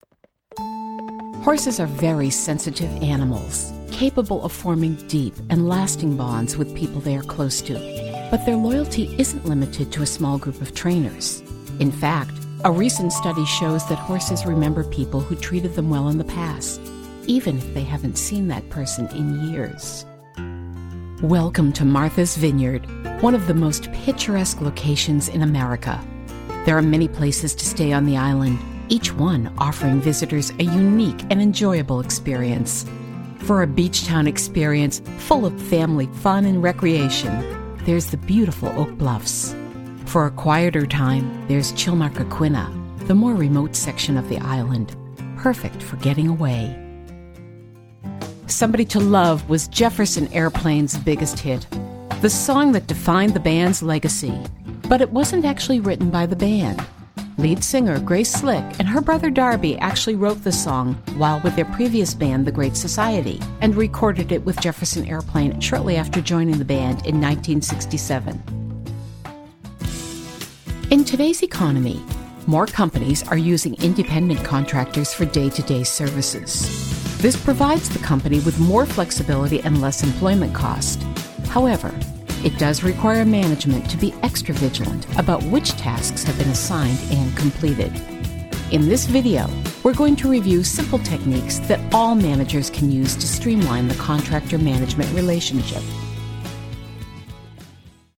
Conversational & engaging VO for commercial, narration & other story-telling projects.
Warm never whining.
Middle Aged